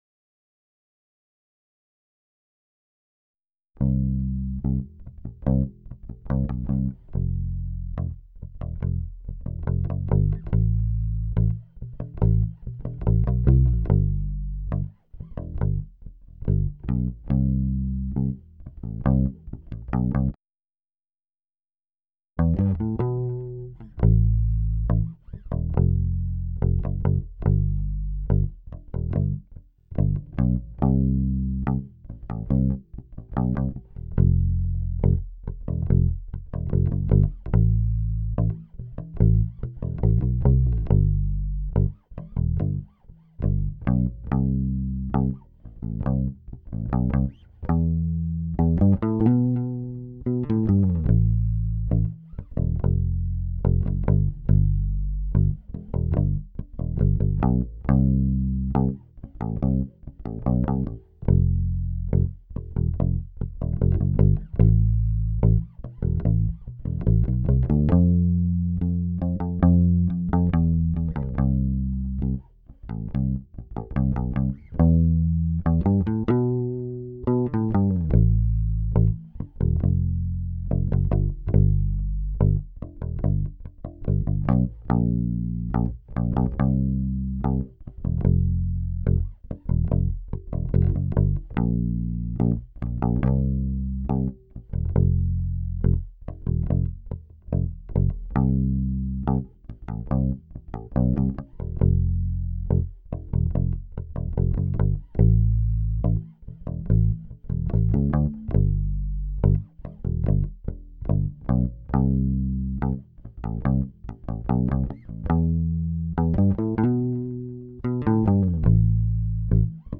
bassguitar.wav